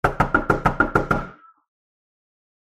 コンコンコンコンと早くノックする音。
玄関のドアをノックする 着信音